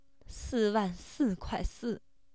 sad